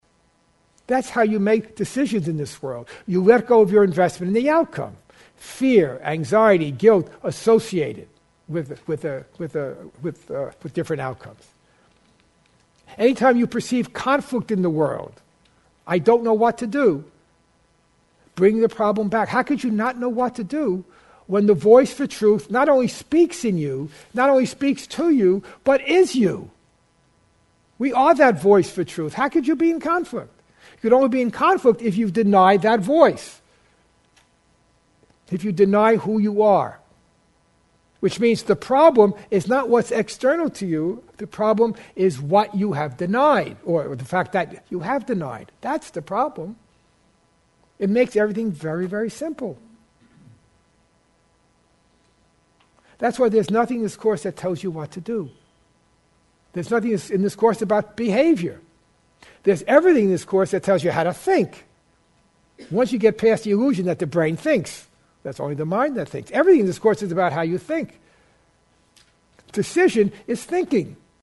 (Excerpt from the class)